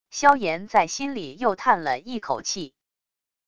萧言在心里又叹了一口气wav音频生成系统WAV Audio Player